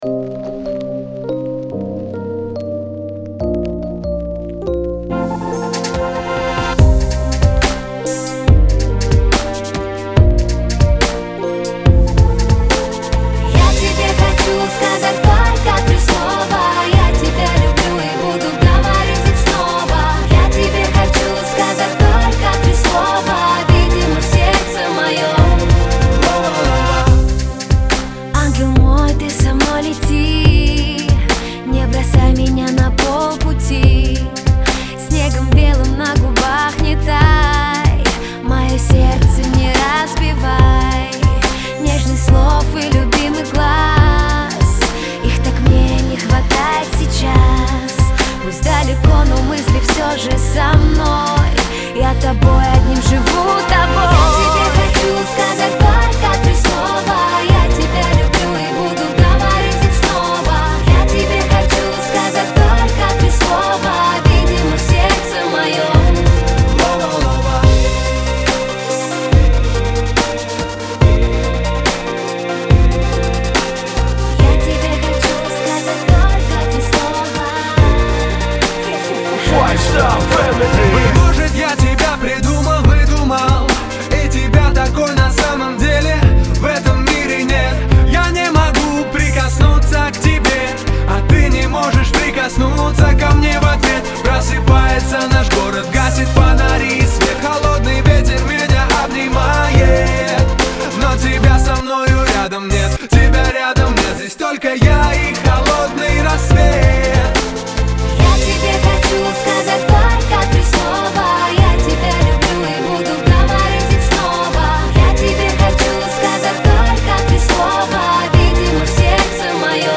Категория: POP